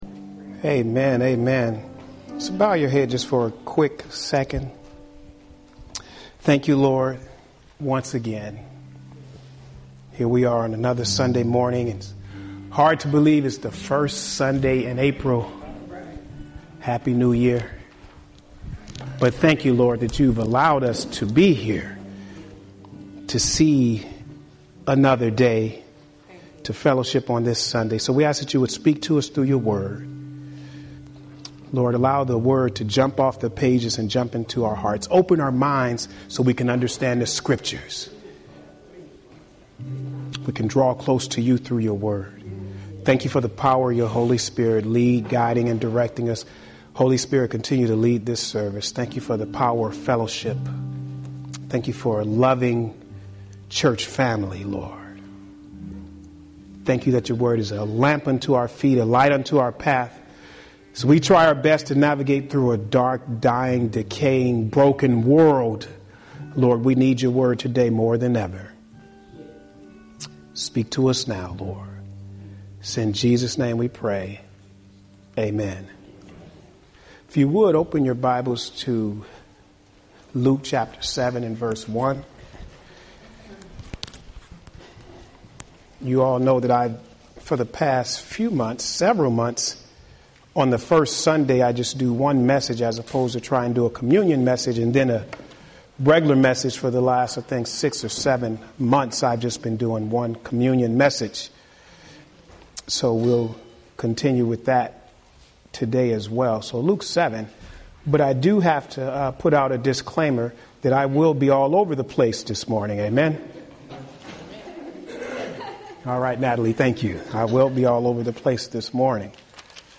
Audio Sermon